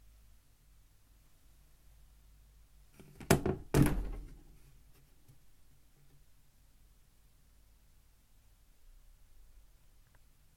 Duration - Environment - Absorption materials, open space. Description - Plastic cabinet, mirrored doors, Clips and clatters when opening centre door.